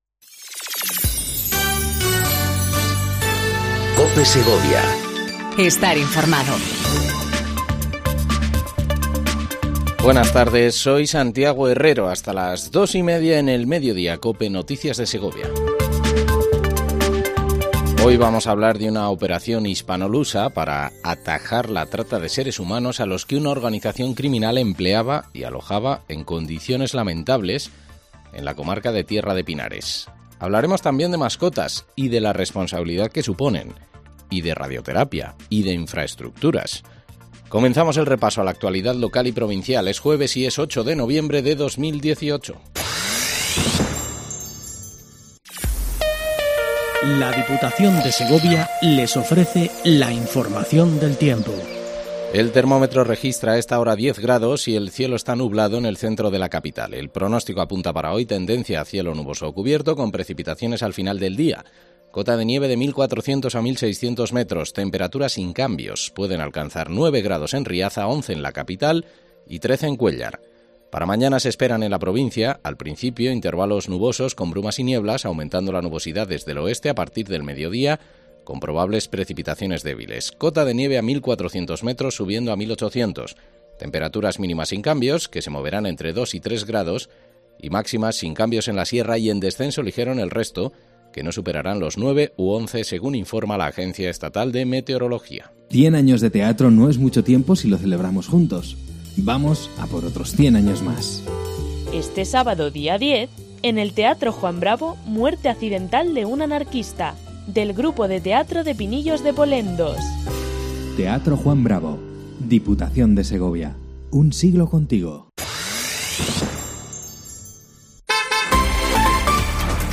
INFORMATIVO MEDIODÍA COPE SEGOVIA 14:20 DEL 08/11/18